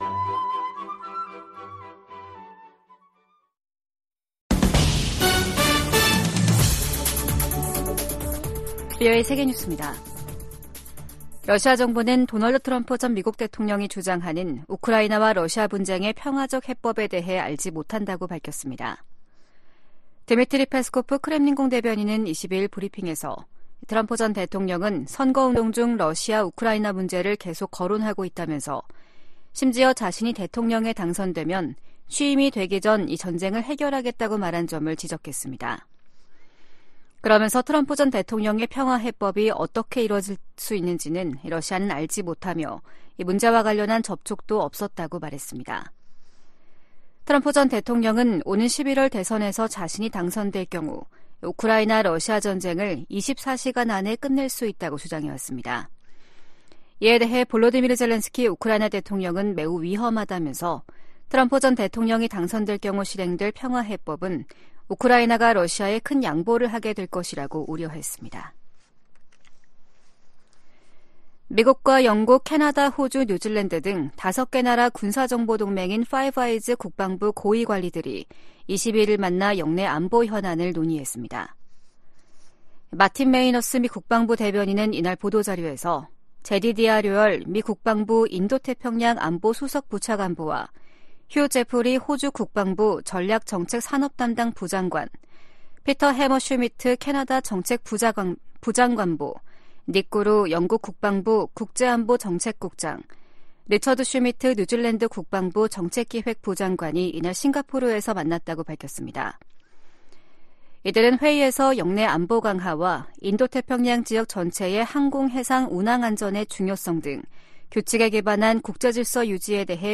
VOA 한국어 아침 뉴스 프로그램 '워싱턴 뉴스 광장' 2024년 1월 23일 방송입니다. 미 국무부가 북한의 수중 핵무기 시험 주장에 도발을 중단하고 대화에 나서라고 촉구했습니다. 백악관은 수중 핵무기 시험 주장과 관련해 북한이 첨단 군사 능력을 계속 추구하고 있음을 보여준다고 지적했습니다. 과거 미국의 대북 협상을 주도했던 인사들이 잇달아 김정은 북한 국무위원장의 최근 전쟁 언급이 빈말이 아니라고 진단하면서 파장을 일으키고 있습니다.